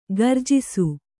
♪ garjisu